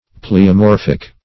\Ple`o*mor"phic\
pleomorphic.mp3